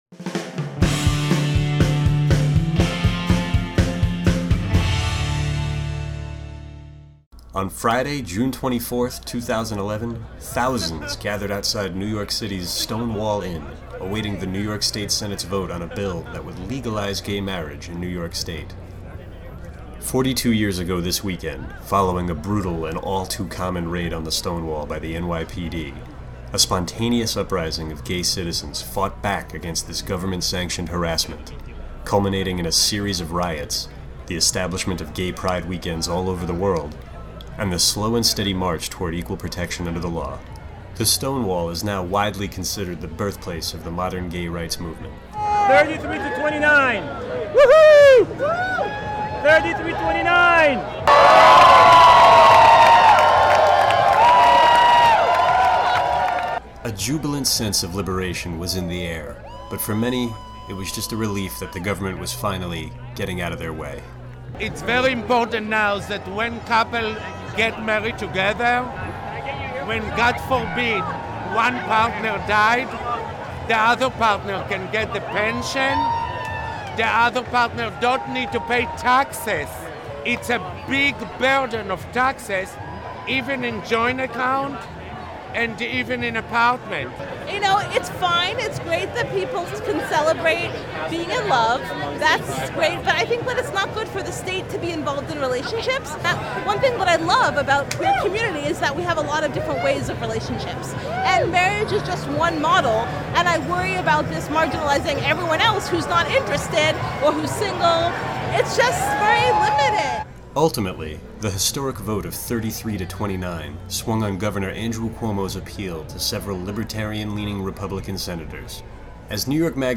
On June 24, 2011, thousands gathered outside New York City's Stonewall Inn, anxiously awaiting the New York State Senate's vote on legalizing gay marriage.
When news came that the bill had passed on a narrow 33-29 vote, the crowd erupted with joy.